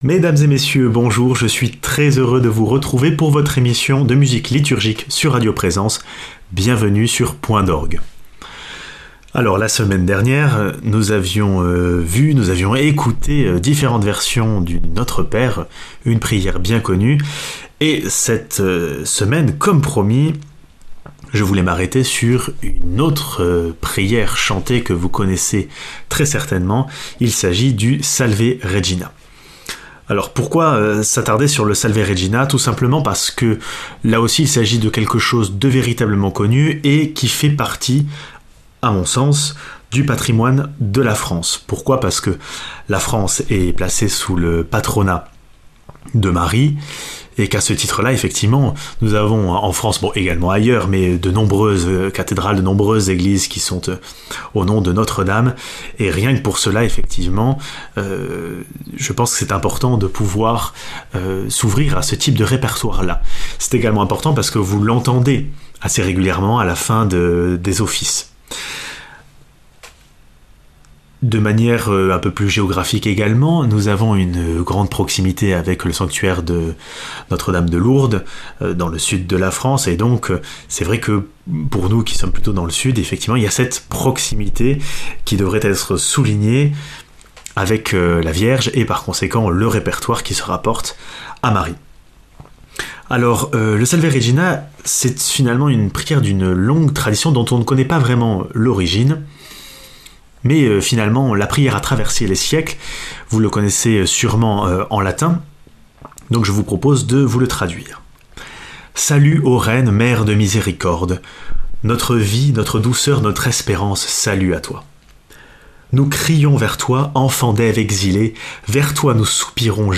[ Rediffusion ] L’un de nos plus anciens chant chrétien a été revisité au cours des siècles.